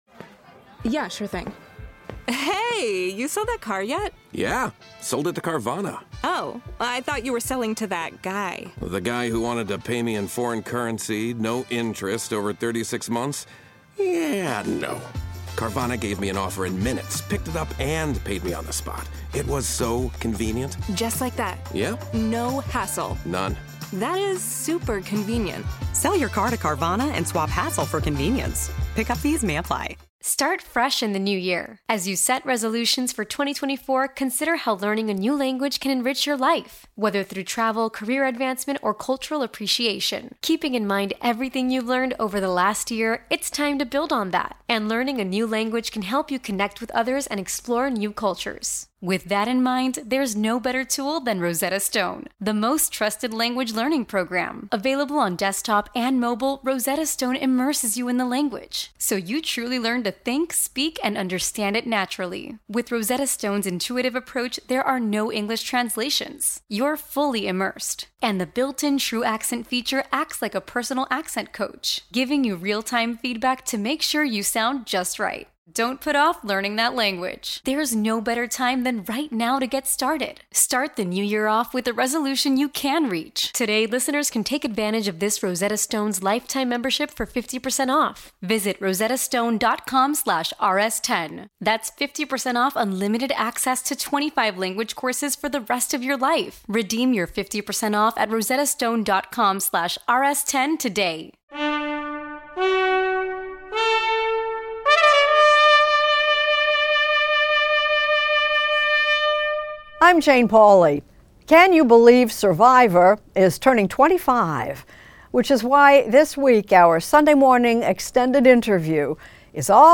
Extended interview: "Survivor" host and showrunner Jeff Probst